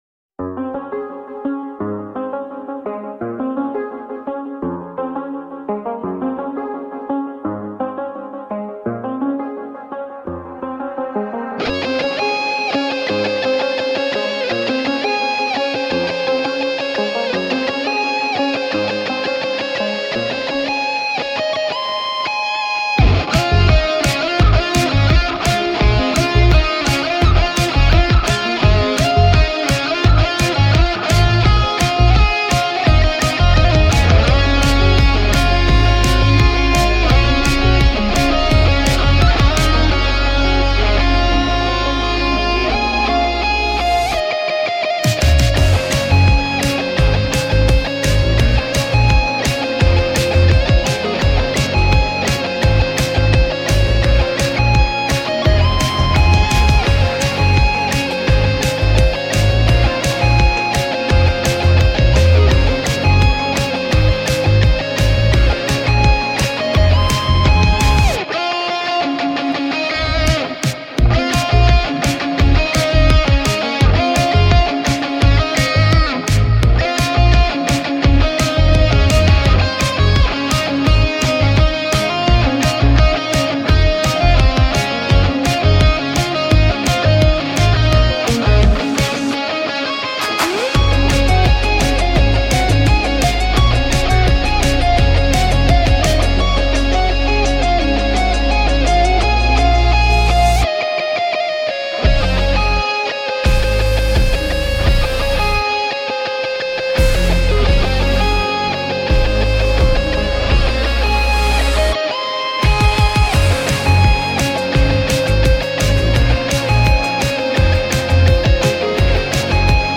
Pop, Rock